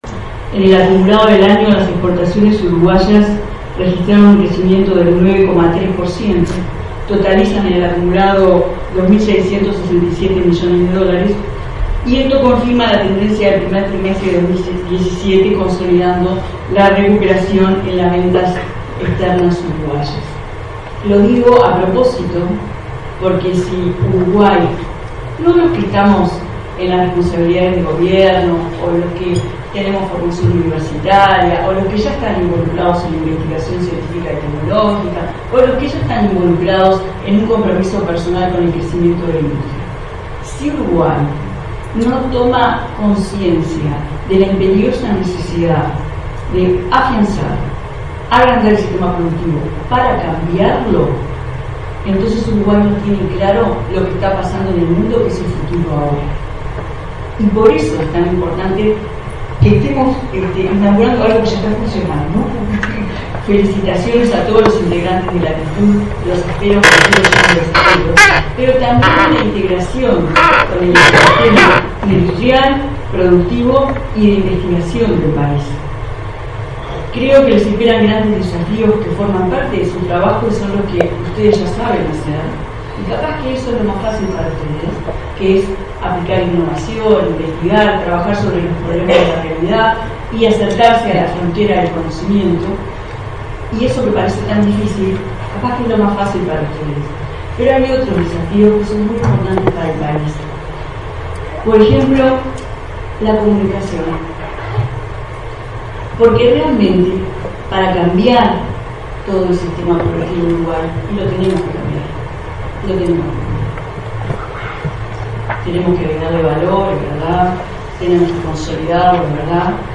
En el acumulado del año las exportaciones uruguayas registraron un crecimiento del 9,3%, lo que confirma la tendencia del primer tristemente de 2017 de recuperación de las ventas, informó la ministra de Industria, Carolina Cosse, en el lanzamiento de la Fundación Latitud. Destacó la necesidad de transformar el sistema productivo con trabajo colectivo y fomentando la comunicación en el desarrollo y promoción de la ciencia.